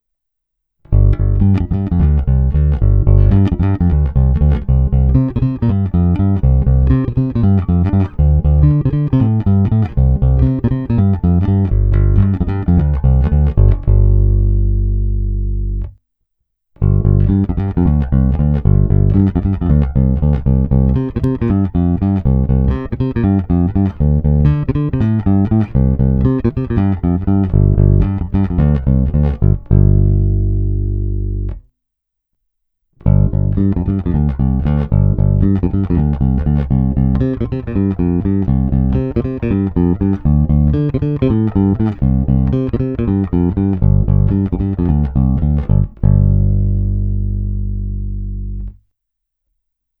Zvuk je tlustý, zvonivý vrčák s okamžitým nástupem pevného tónu.
Není-li uvedeno jinak, následující nahrávky jsou provedeny rovnou do zvukovky a dále kromě normalizace ponechány bez úprav.
Tónová clona vždy plně otevřená.